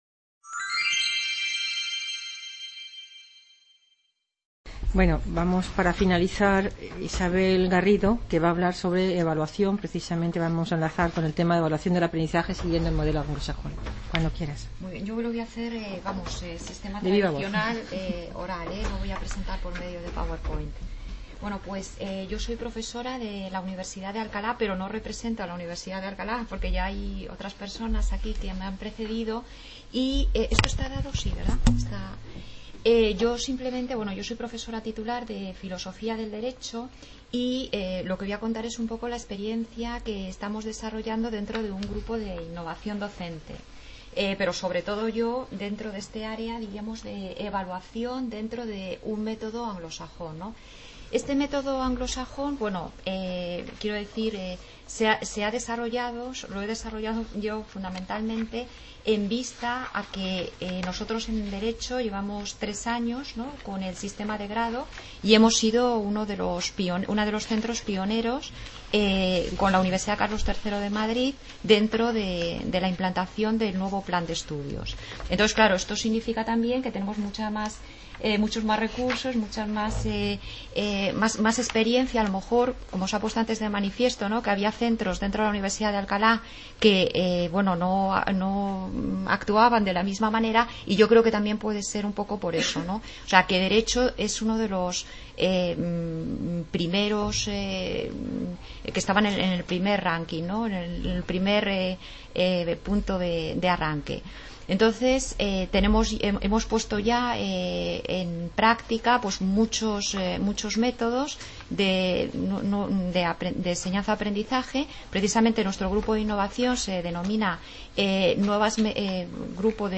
| Red: UNED | Centro: UNED | Asig: Reunion, debate, coloquio...